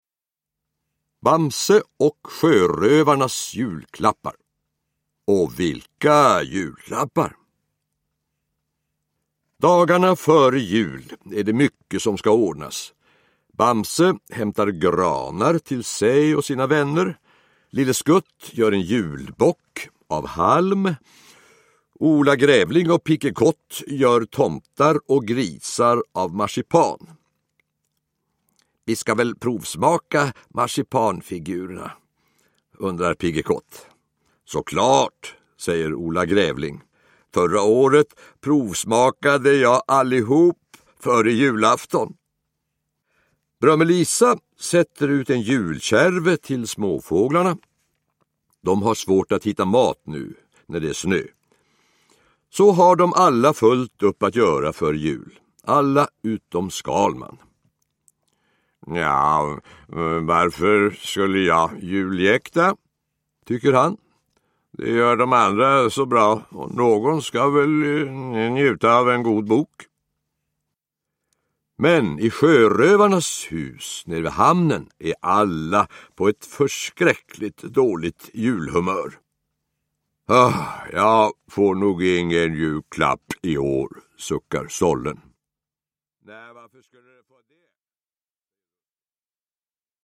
Bamse och sjörövarnas julklappar – Ljudbok – Laddas ner
Uppläsare: Olof Thunberg